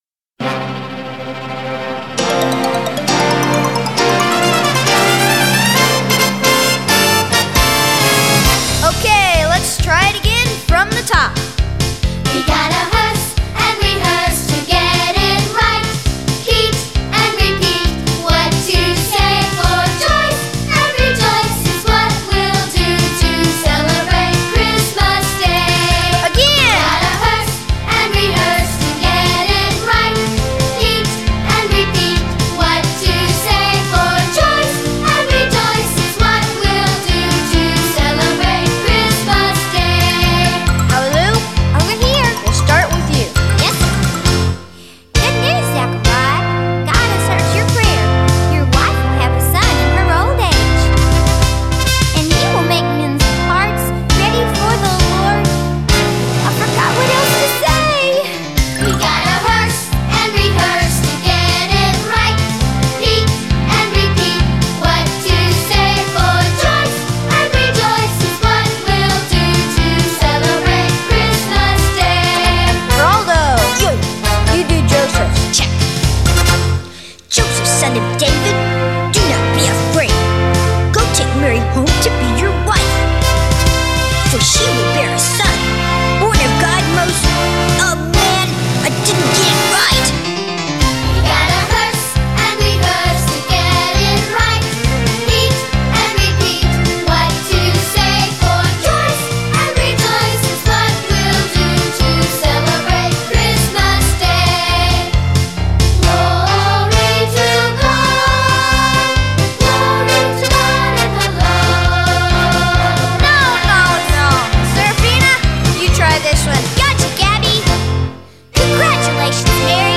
Opening song
Christmas musical